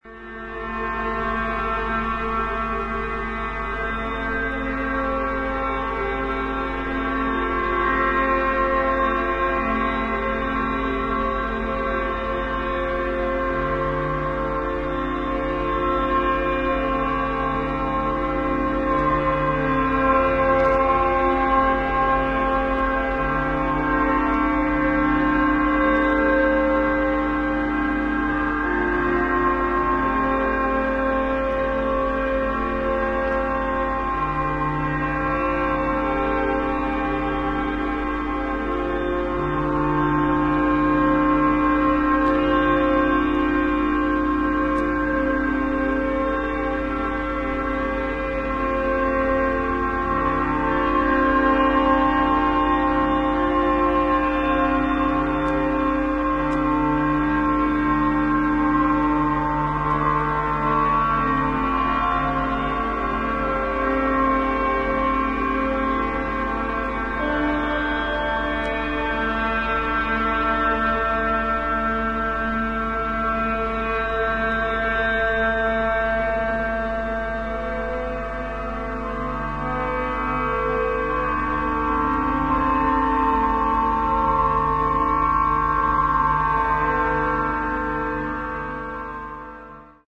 アンビエント感のあるドローン・サウンドが幾重にもレイヤーされ、深みのある重厚な世界観を披露している素晴らしい内容。